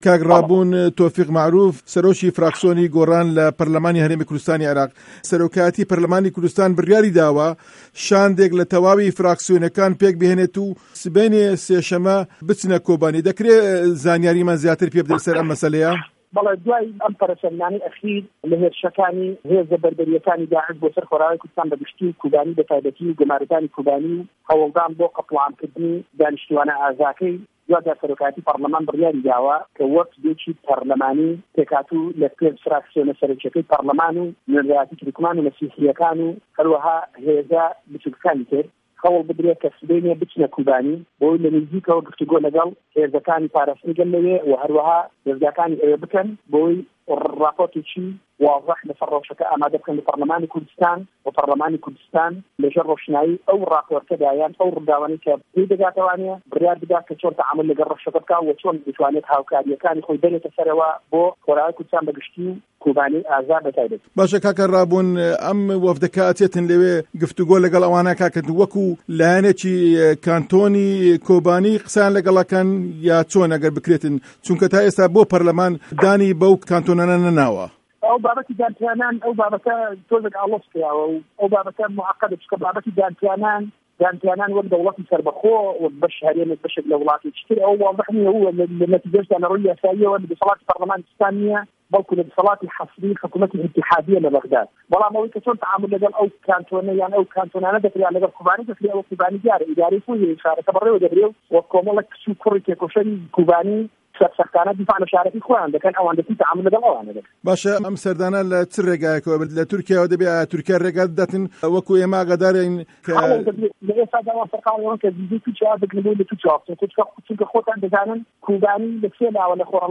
وتووێژی ڕابون تۆفیق مه‌عروف